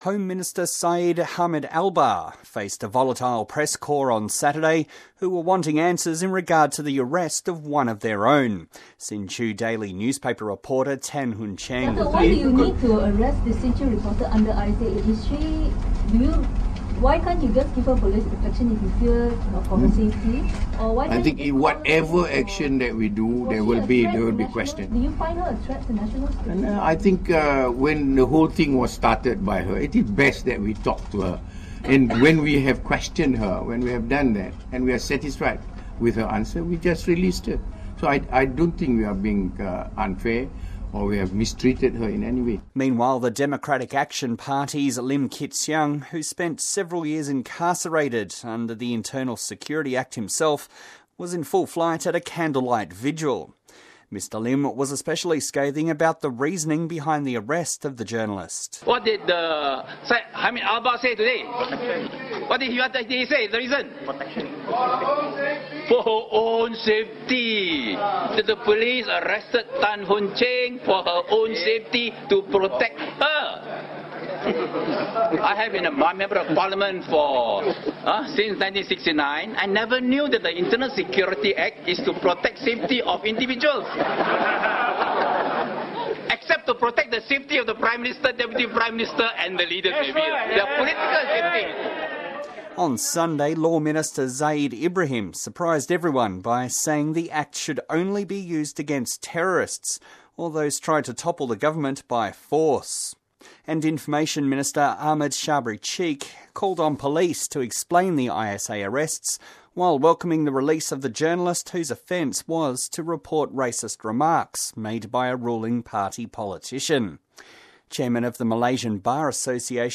TALENT: Syed Hamid Albar, Malaysia’s home affairs minister; Lim Kit Siang of the Democratic Action Party